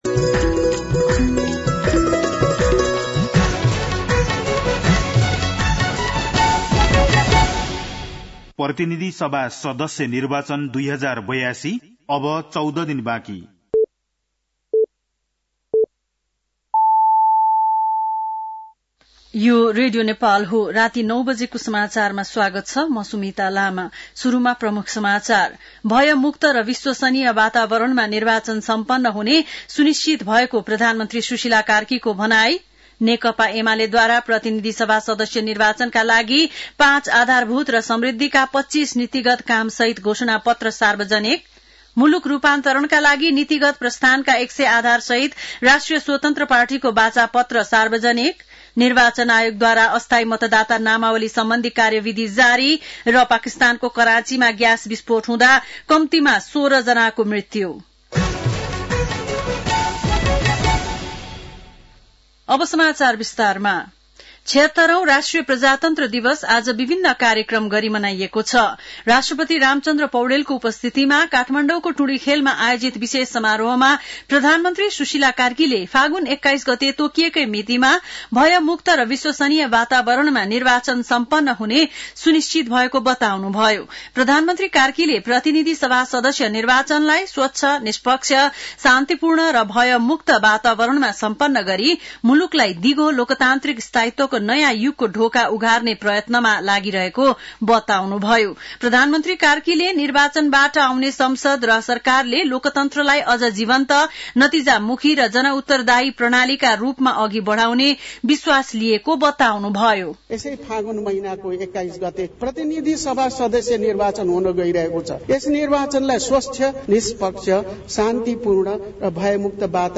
बेलुकी ९ बजेको नेपाली समाचार : ७ फागुन , २०८२
9-PM-Nepali-NEWS-11-07.mp3